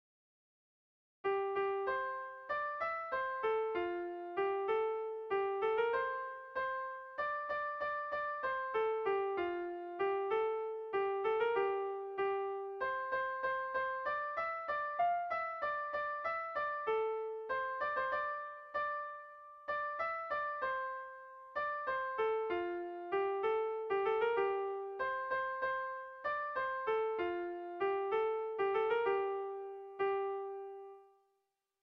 Gabonetakoa
ABDB2A2